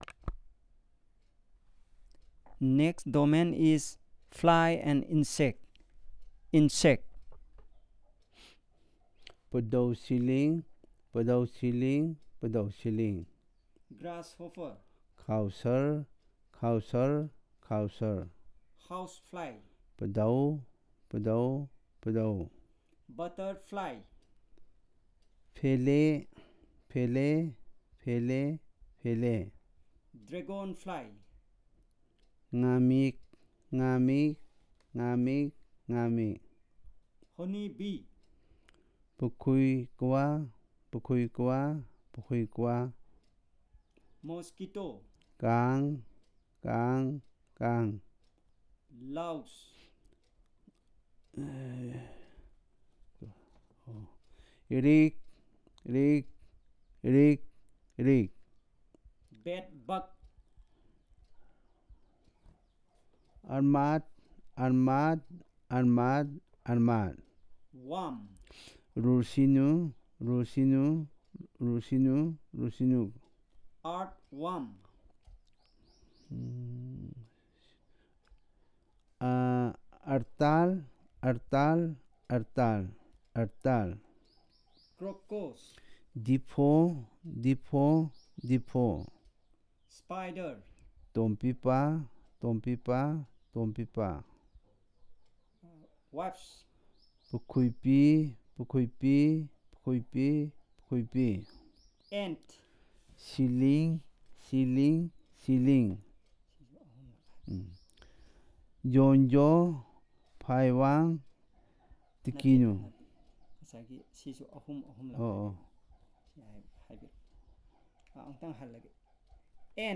Elicitation of words about flies and insects